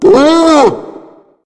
main Divergent / mods / Soundscape Overhaul / gamedata / sounds / monsters / psysucker / hit_0.ogg 22 KiB (Stored with Git LFS) Raw Permalink History
hit_0.ogg